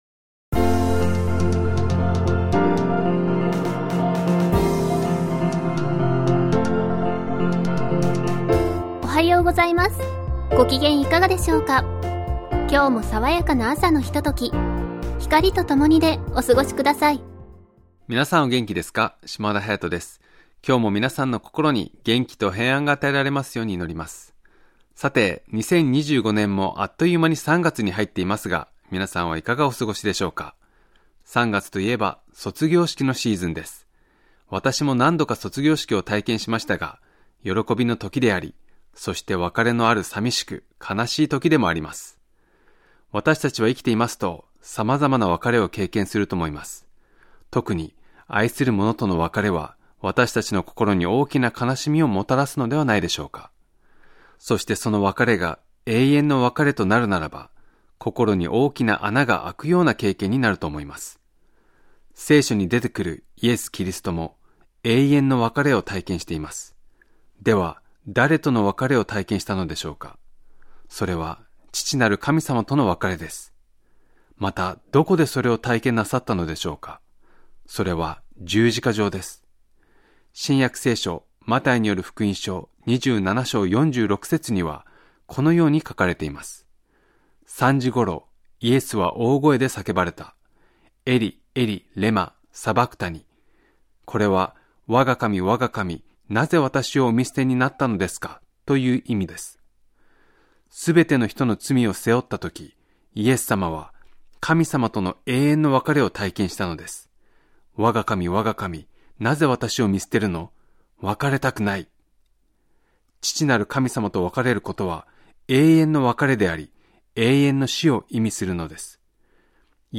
ラジオNIKKEIで放送中のキリスト教番組です。